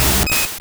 Cri de Kabuto dans Pokémon Or et Argent.